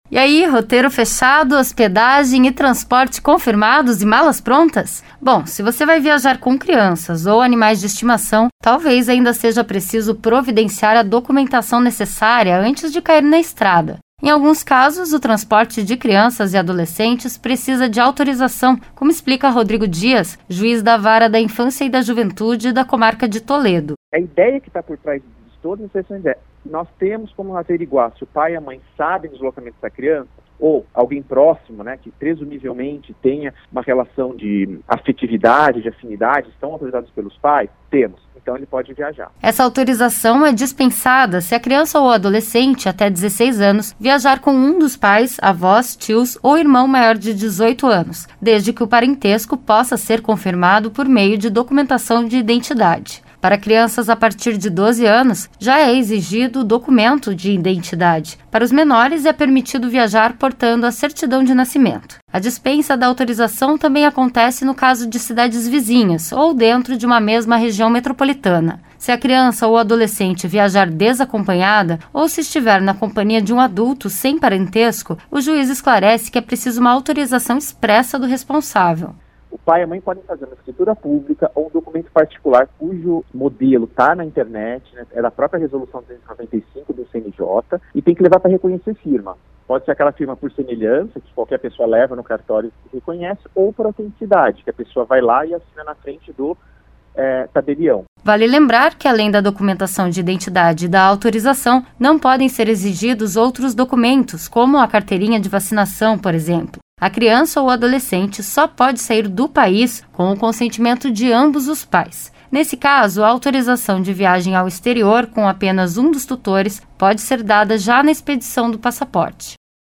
Quais os documentos necessários para viajar com crianças e animais de estimação e quando é preciso autorização? Saiba todos os detalhes na última reportagem da série Férias sem Cair em Roubada.